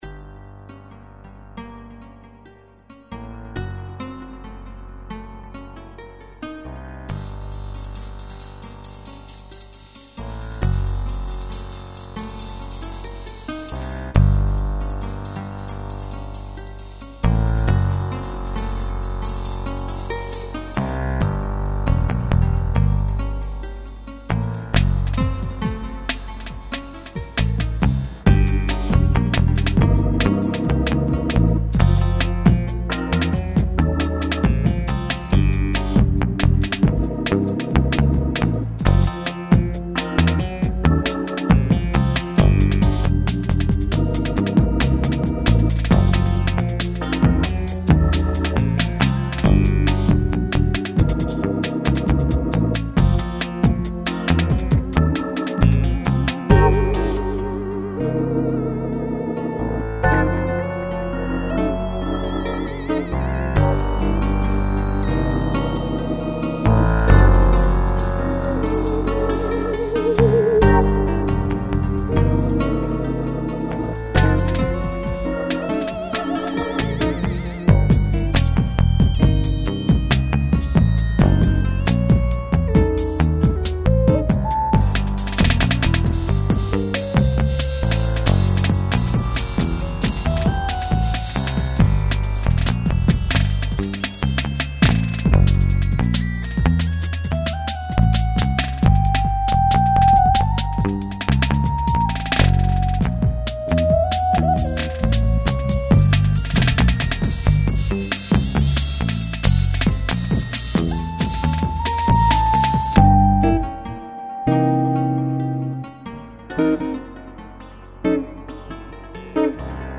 _____G diminished funk/I.D.M...